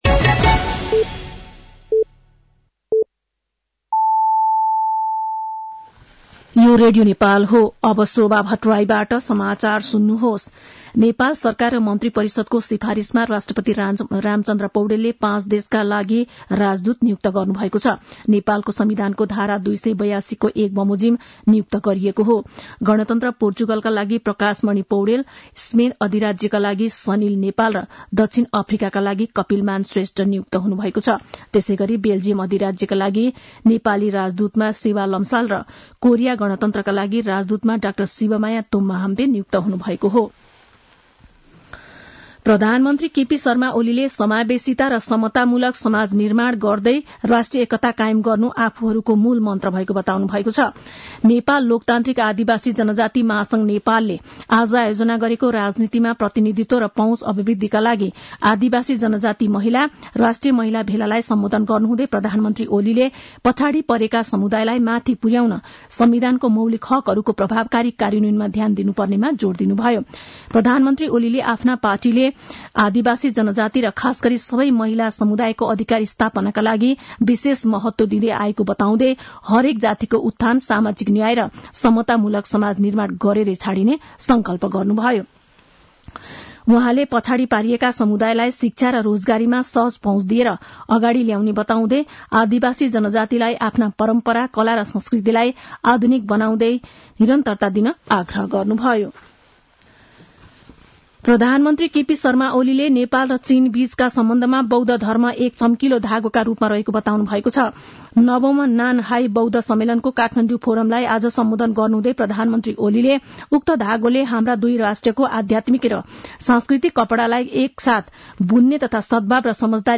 साँझ ५ बजेको नेपाली समाचार : २९ मंसिर , २०८१
5-pm-nepali-news-8-28.mp3